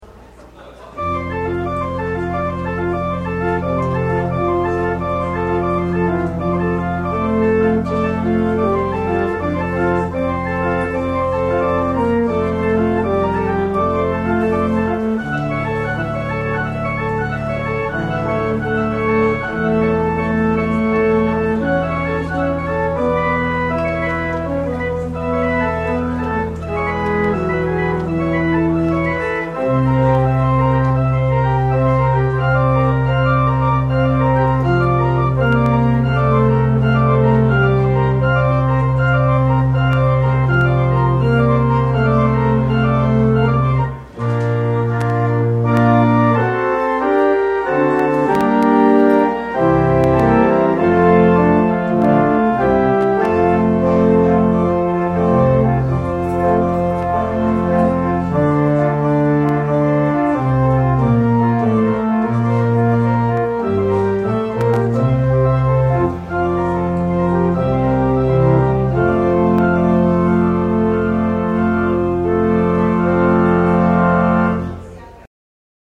24 Postlude.mp3